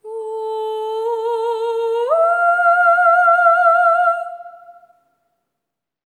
ETHEREAL05-L.wav